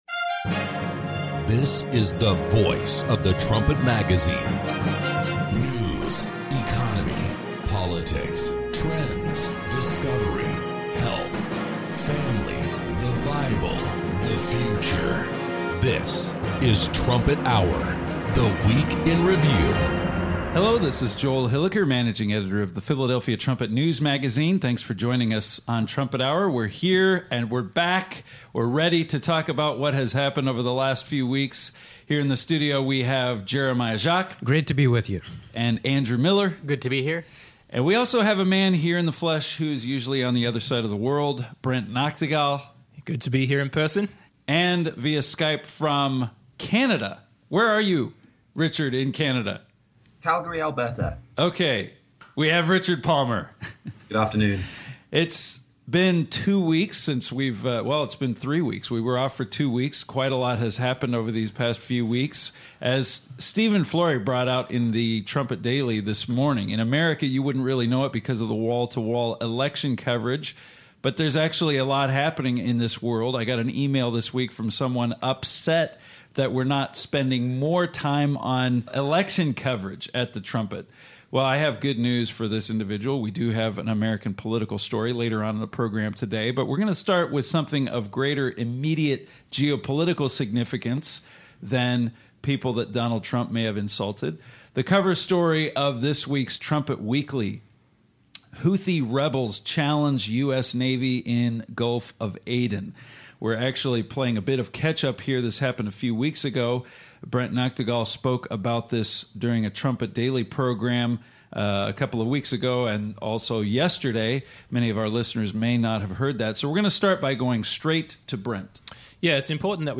Join the discussion as Trumpet staff members compare recent news to Bible prophecy.